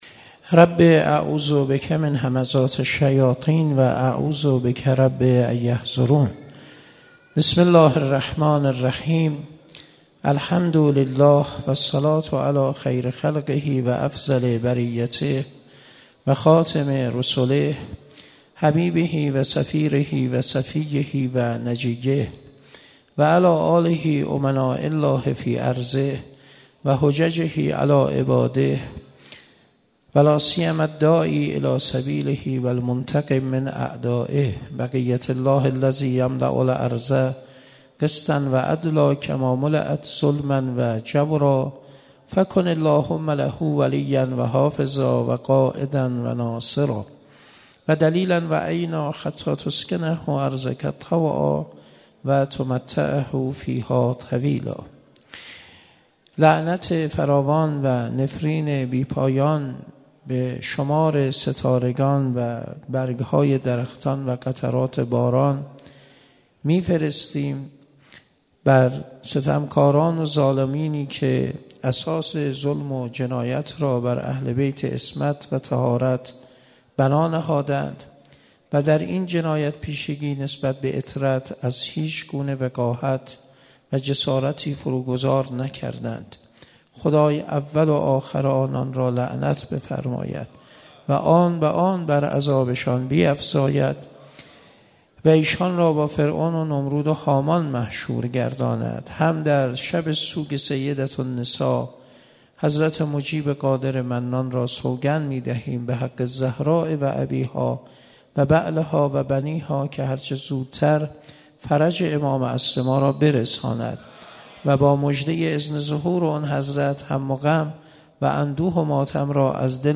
29 بهمن 96 - حسینیه کربلایی ها - سخنرانی